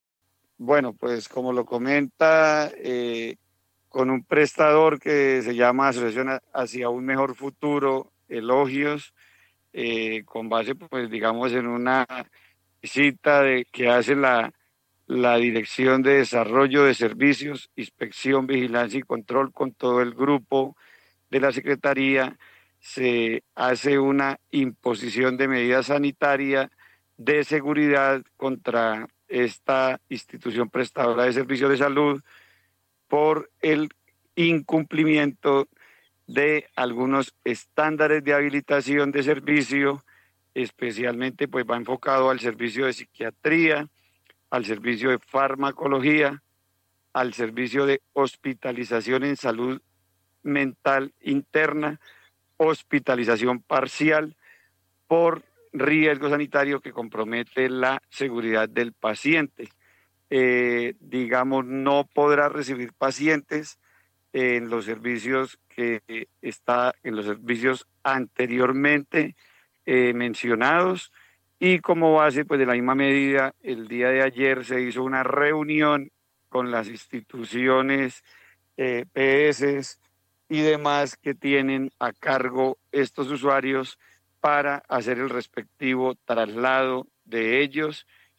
Edwin Prada, Secretario de Salud